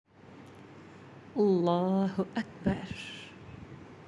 How To Say Allahu Akbar Tutorial
While in Allahu Akbar both word are read as one phrase joining them with the sign of dumma.